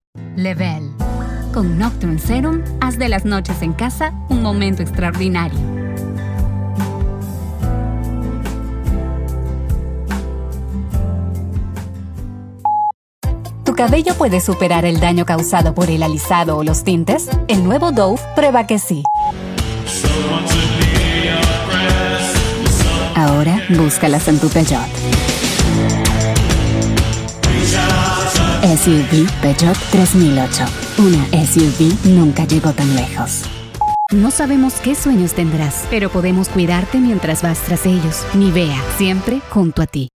Espagnol (péruvien)
Naturel
Chaleureux
Maternelle